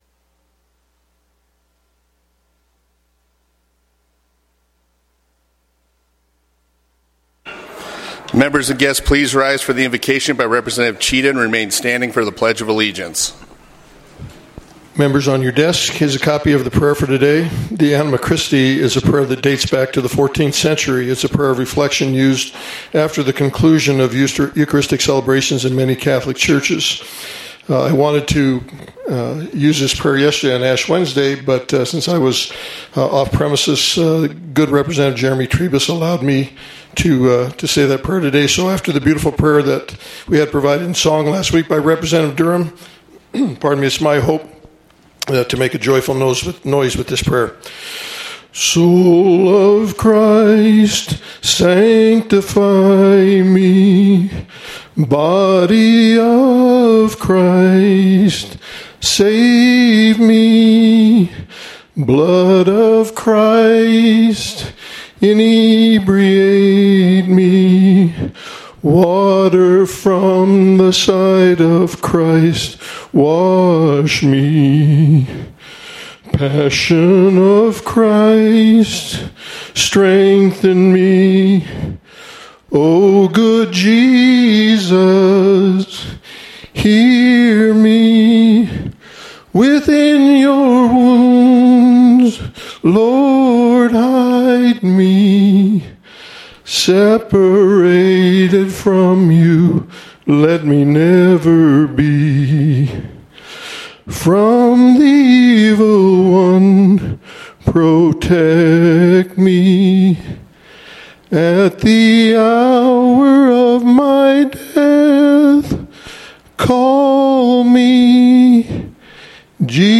House Floor Session